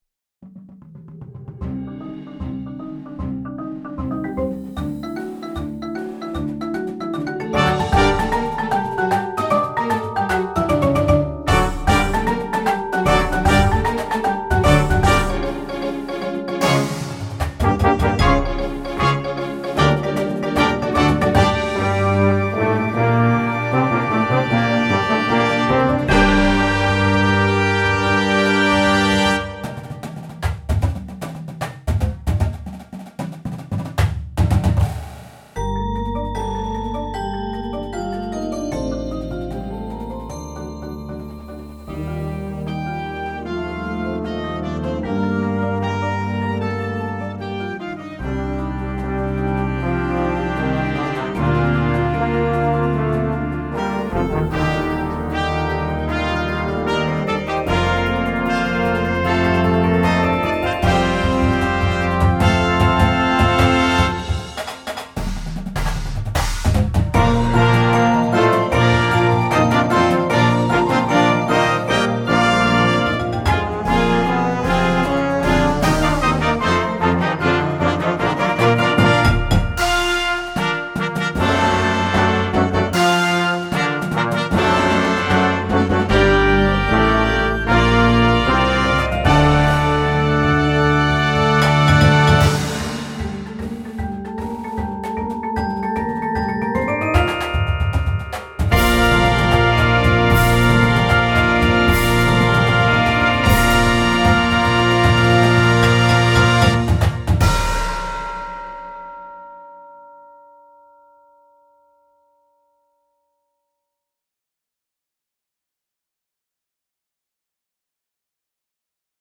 Mvt. 3 (LIVE)